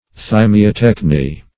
thymiatechny - definition of thymiatechny - synonyms, pronunciation, spelling from Free Dictionary
thymiatechny.mp3